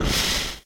steamEngineOperate.ogg